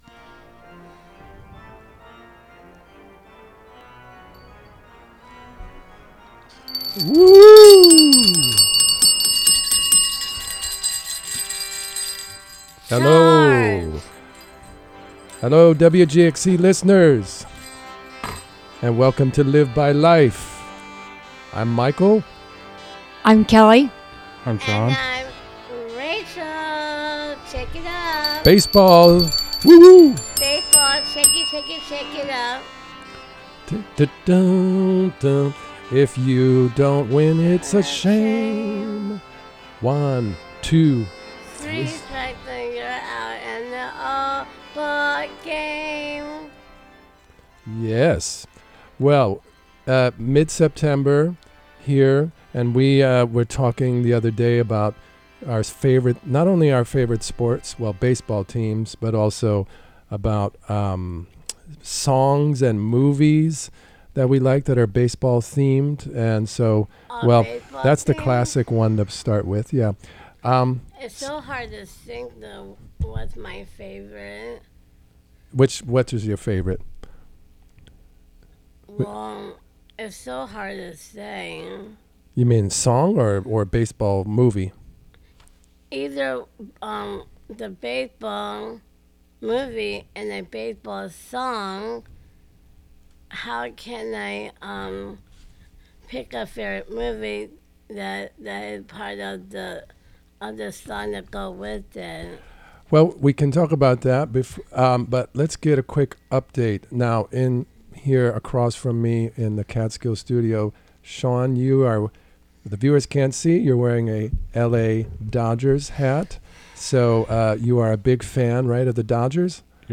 Members of the Camphill Hudson Radio Group from Camphill Hudson make their own radio.